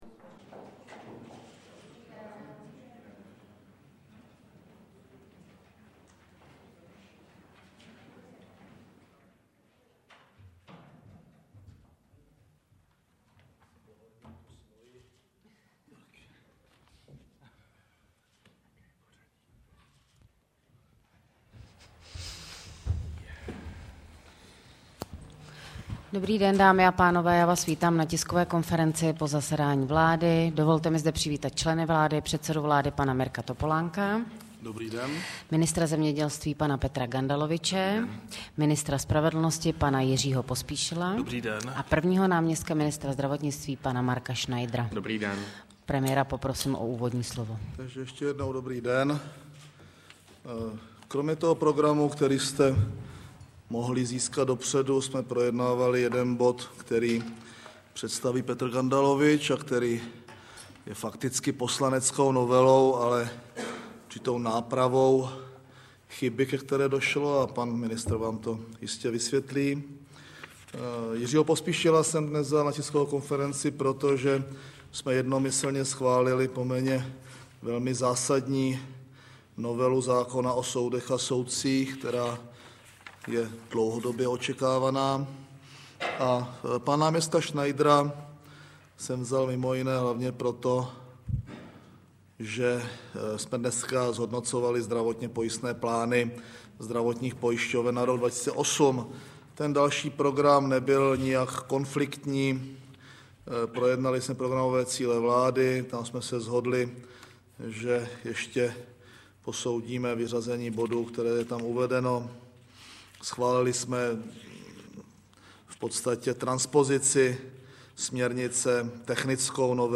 Tisková konference předsedy vlády ČR Mirka Topolánka po jednání vlády 4. 2. 2008-zvukový záznam
Tisková konference po zasedání vlády ČR v pondělí 4. února 2008: